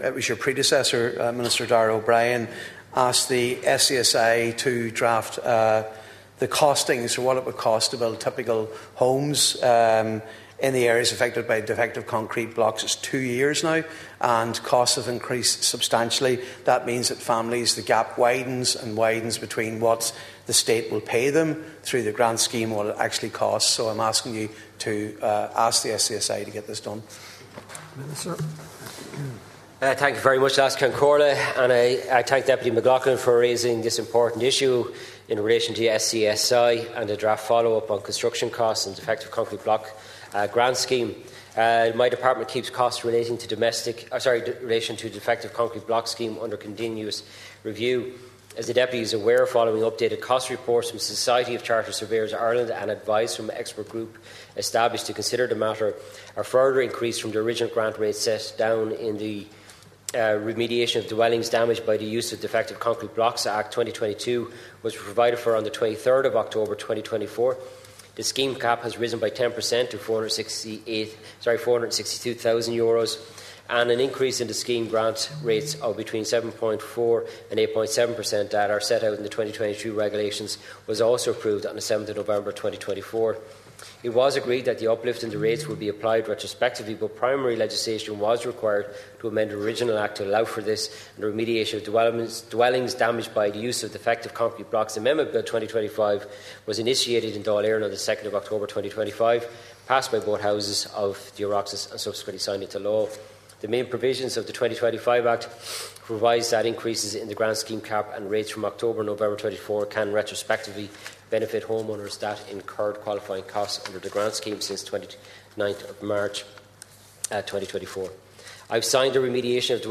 Minister James Browne was speaking in the Dail in answer to a question from Donegal Deputy Padraig MacLochlainn, who is seeking a review of the rates paid under the scheme.